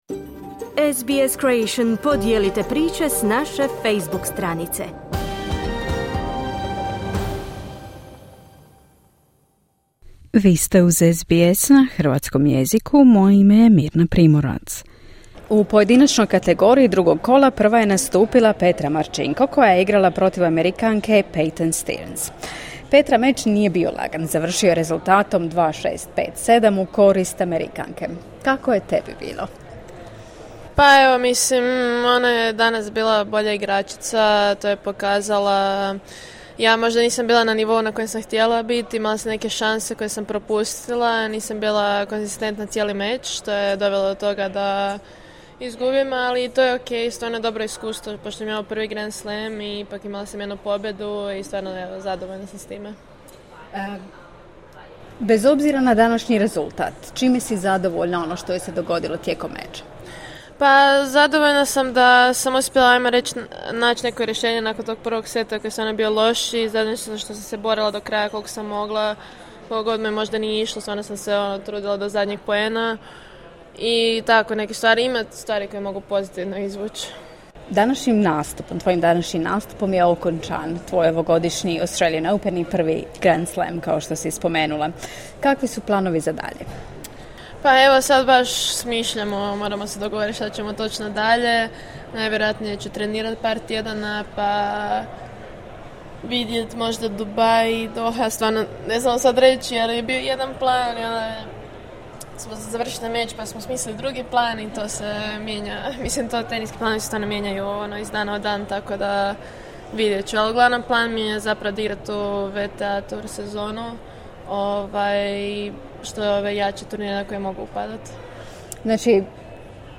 U drugom kolu Australian Opena Petra Marčinko poražena je od Amerikanke Peyton Stearns rezultatom 6:2, 7:5. Iako se turnir nije završio pobjedom, Petra za naš radio donosi svoja iskustva s najveće svjetske pozornice, dojmove s terena i planove za budućnost.